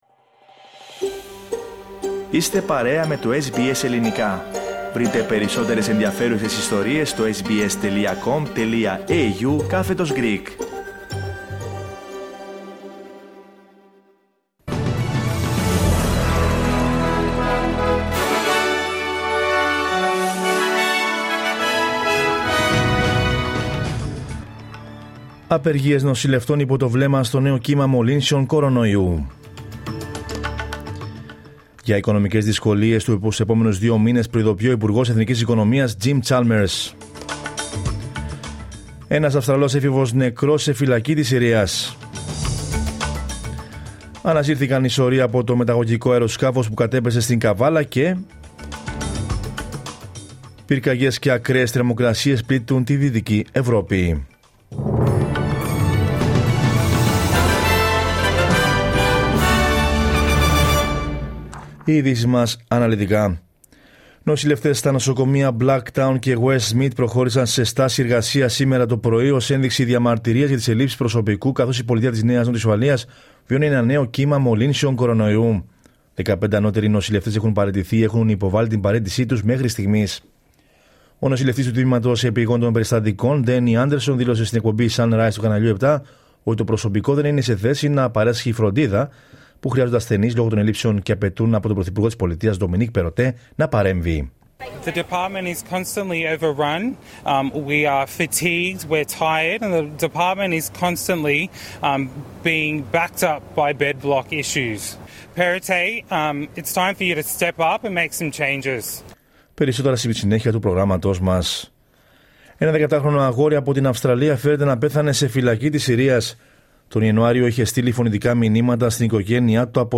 Δελτίο Ειδήσεων Δευτέρα 18.07.22